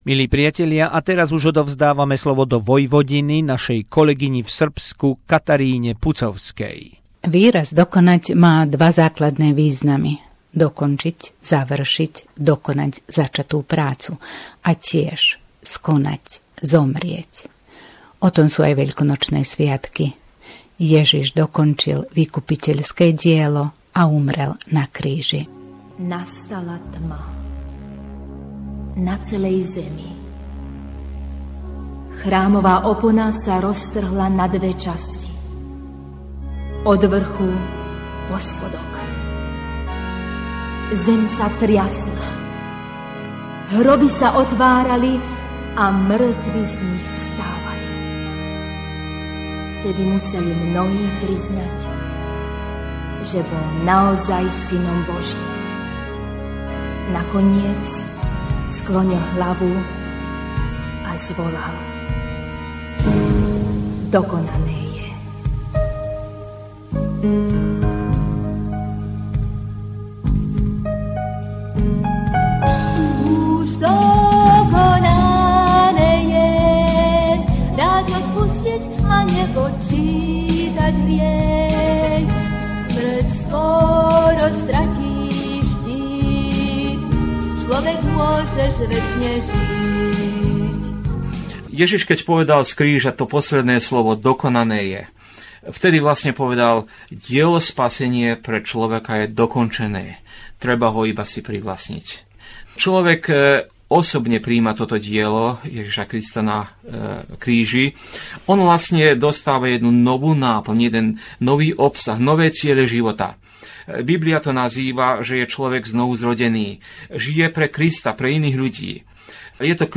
Veľkonočná zvuková pohľadnica